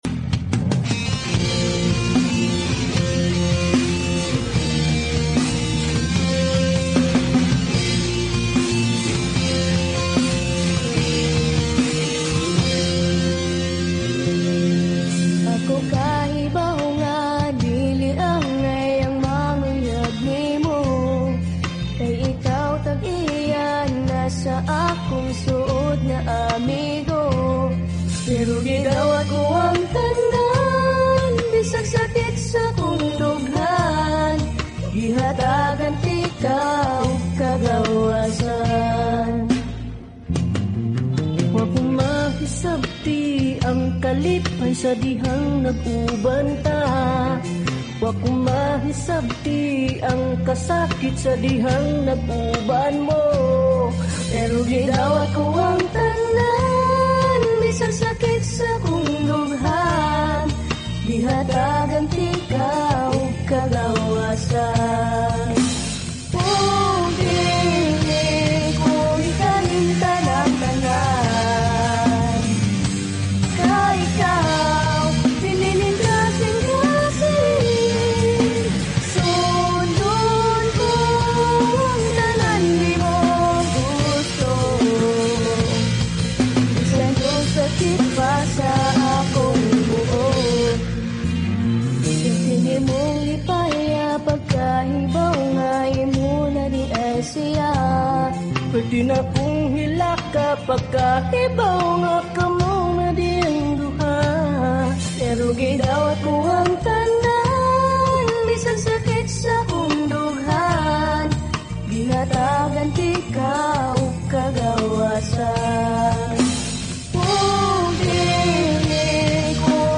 heartfelt Bisaya love song